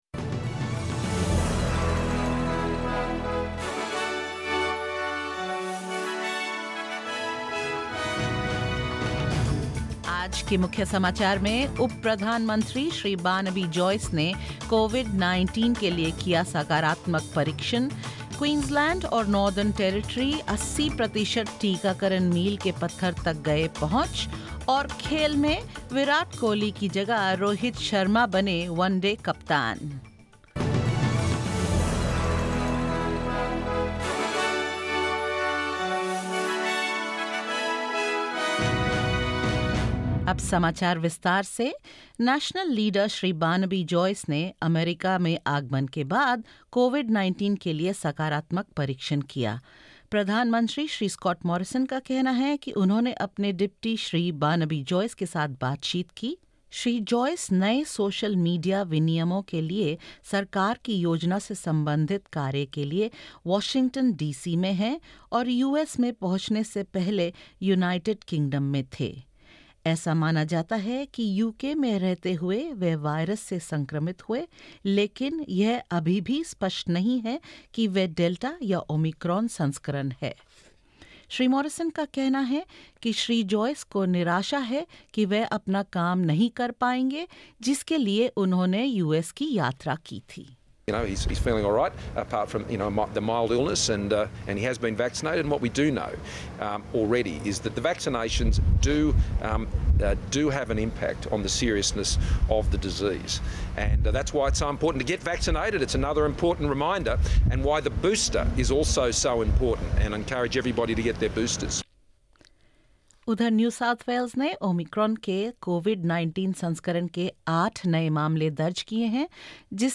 In this latest SBS Hindi news bulletin of Australia and India: Deputy Prime Minister Barnaby Joyce tests positive for COVID-19 on a work trip in the United States; Queensland and Northern Territory meet their 80 per cent vaccination targets; Rohit Sharma replaces Virat Kohli as India's One Day International captain and more.